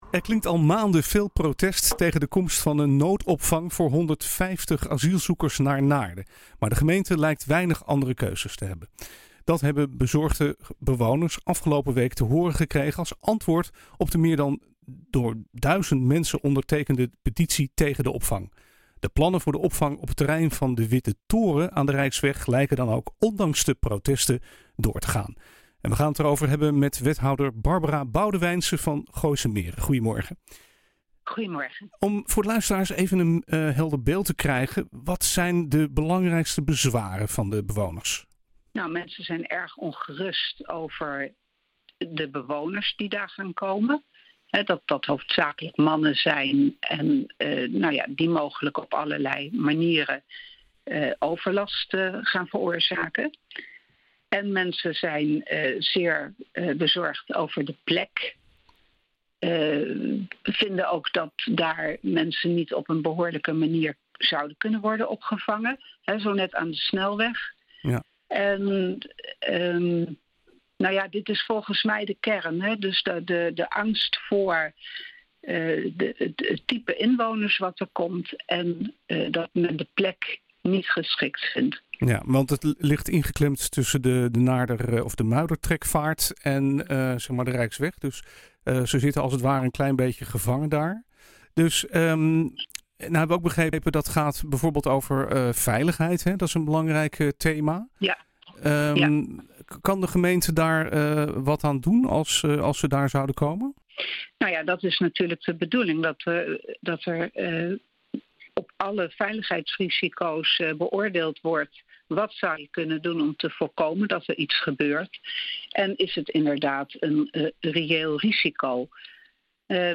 Luister het hele interview met wethouder Barbara Boudewijnse in het radioprogramma NH Gooi Zaterdag terug via deze link